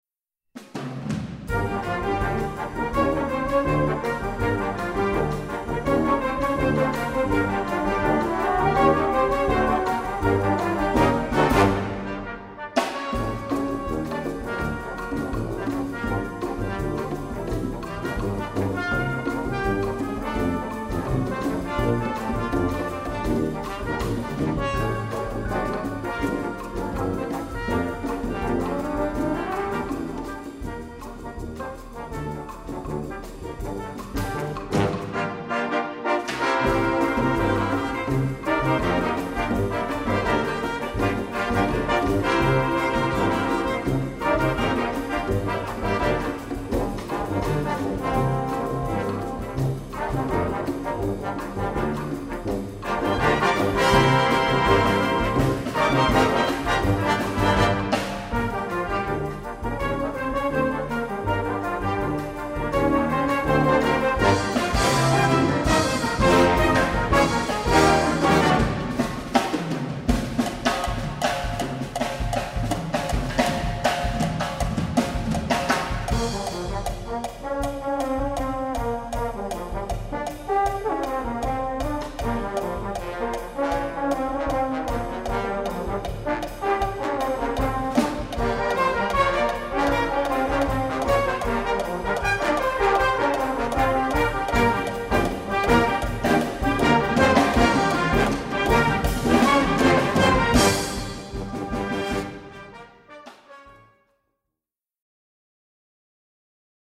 Bossa-Nova-Song